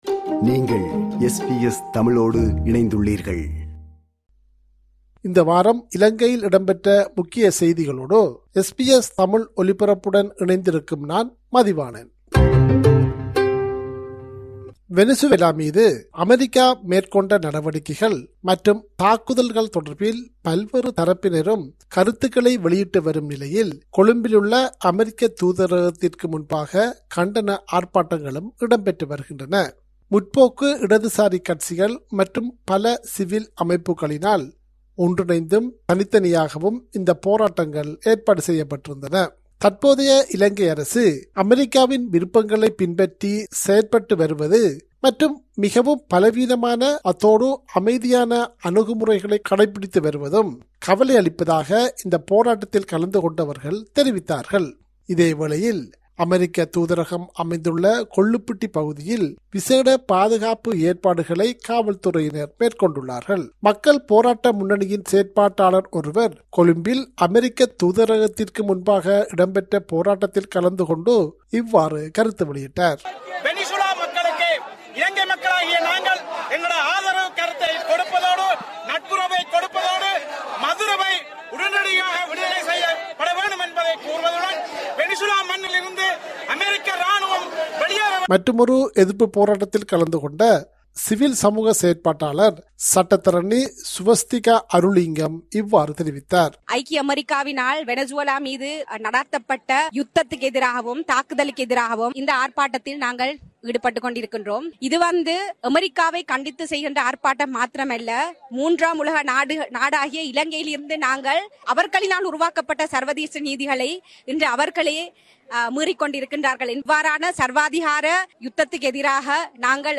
இலங்கை : இந்த வார முக்கிய செய்திகள்